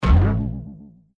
target_trampoline_2.ogg